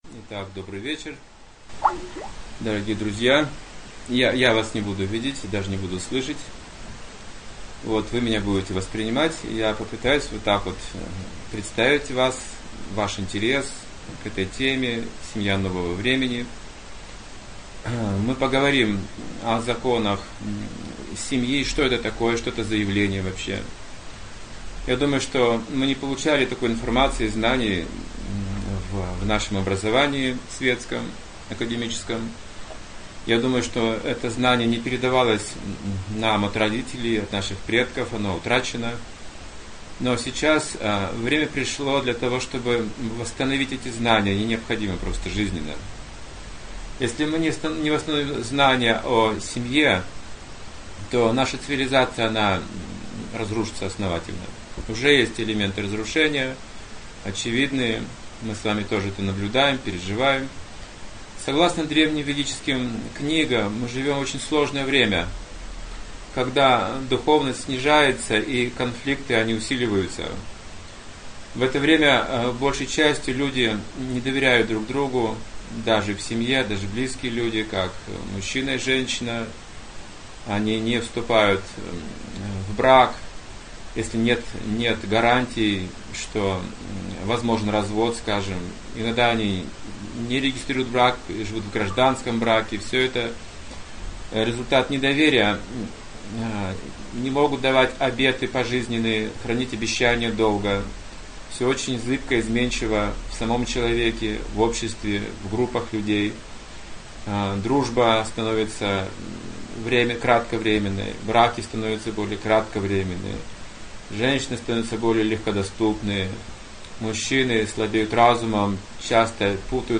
Вебинар Семья нового времени. В чем высший смысл семейной жизни (2015, Алматы)